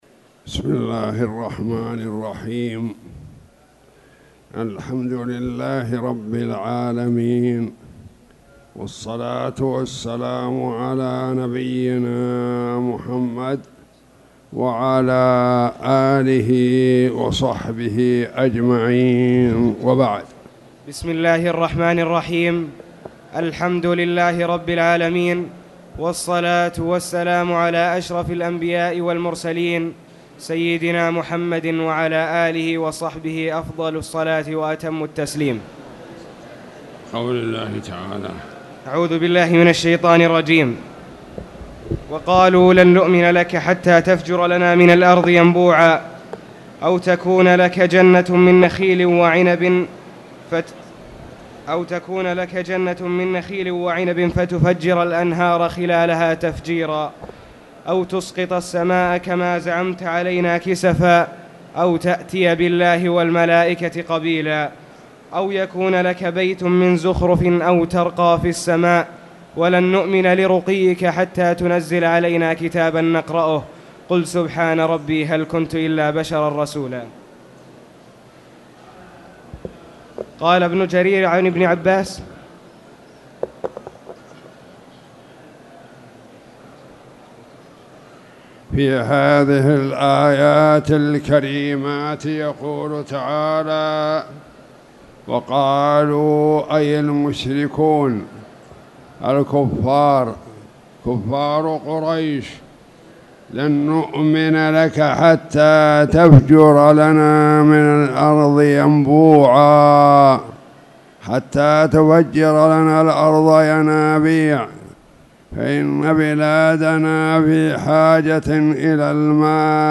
تاريخ النشر ٩ صفر ١٤٣٨ هـ المكان: المسجد الحرام الشيخ